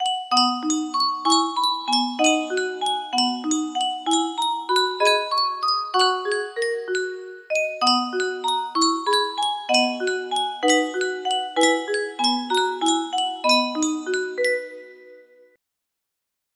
Yunsheng Music Box - It Came Upon a Midnight Clear 265Y music box melody
Full range 60